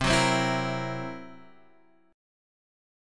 C7b5 chord